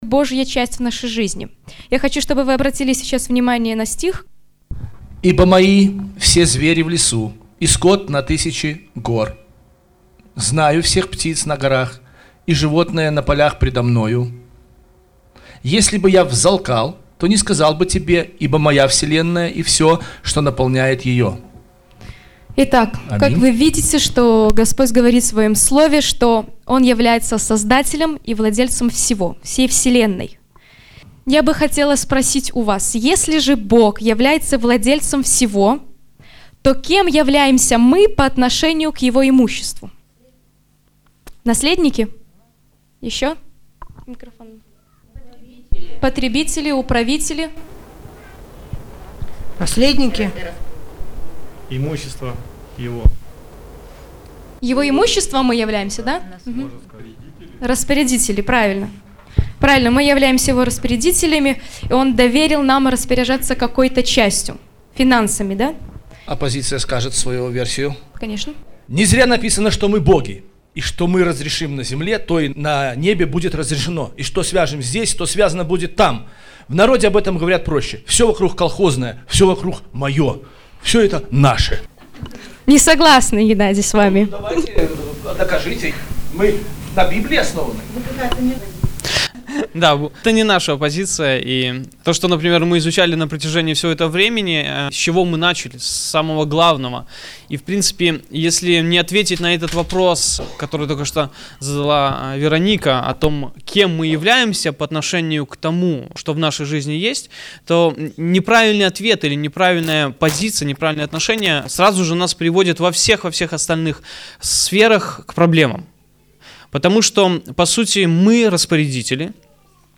В дискуссии принимали участие все.
Мы выкладываем по мере готовности аудио-версию семинара, прошедшего в субботу 21 апреля 2012 года в стенах церкви «Святая Иоппия».